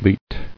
[leet]